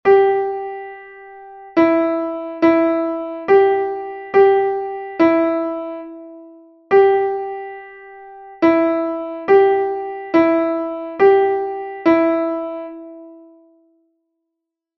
Melodic reading practice
Exercise 4 G and E